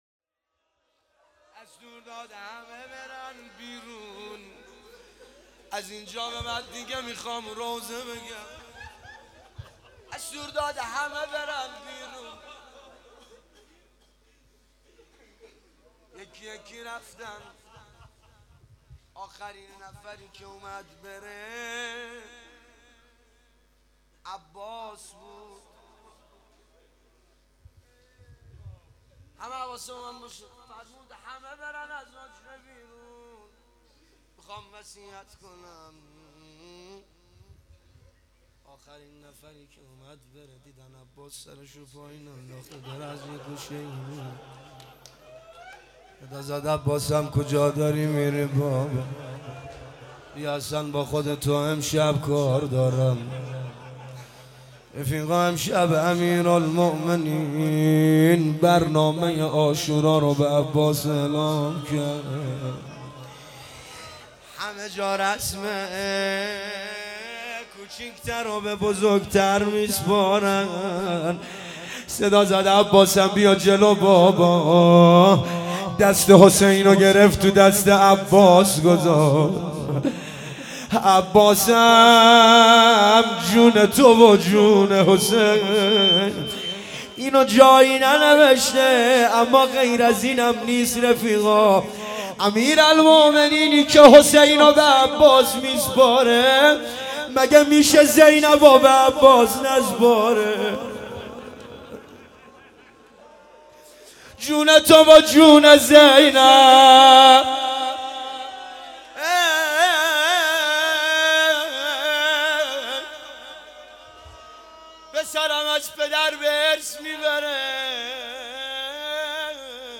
روضه امام علی علیه السلام